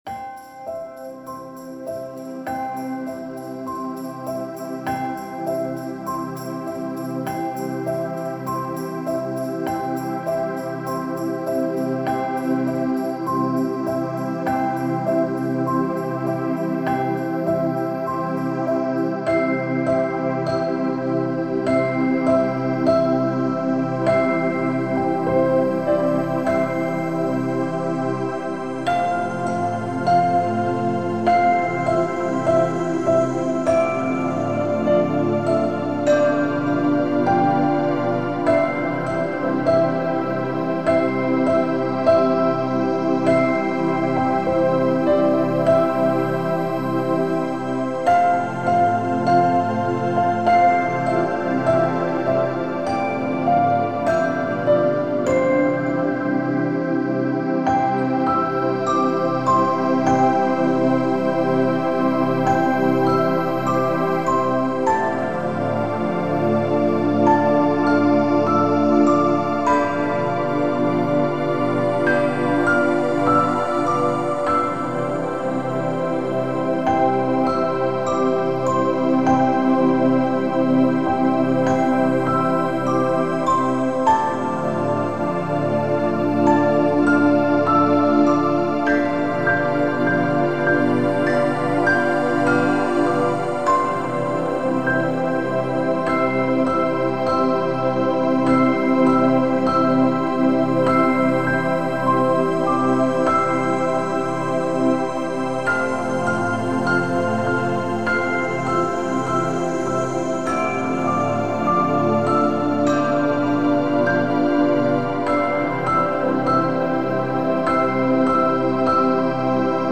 Ringtone